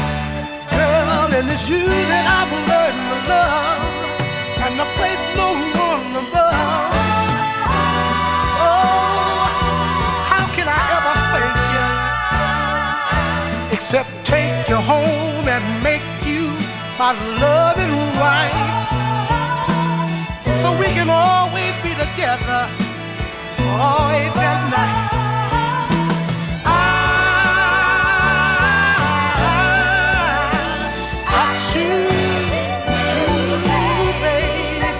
Жанр: Рок / R&B / Соундтрэки / Соул / Фанк